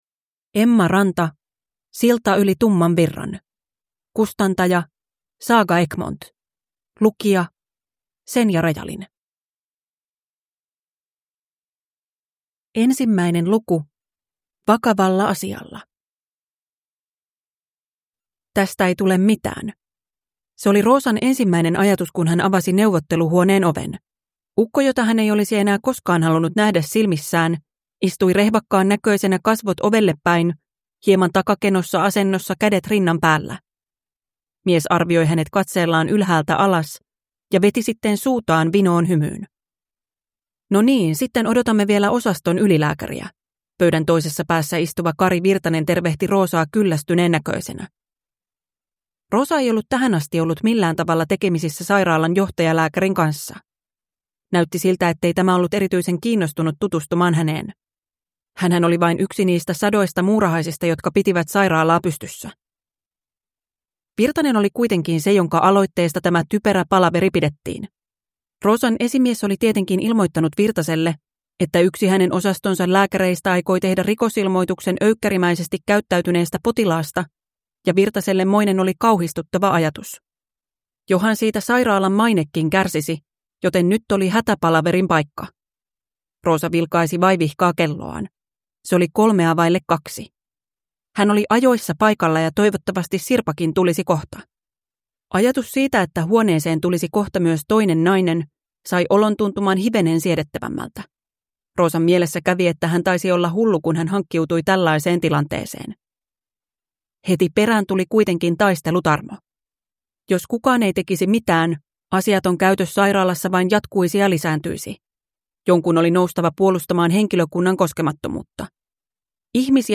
Silta yli tumman virran – Ljudbok